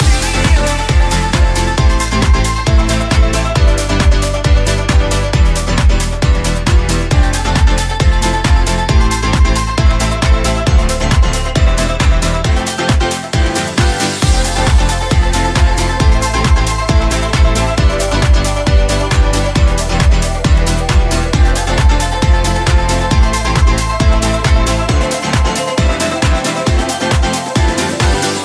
Genere: pop,dance,deep,disco,house.groove,latin,hit